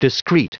Prononciation du mot discrete en anglais (fichier audio)
Prononciation du mot : discrete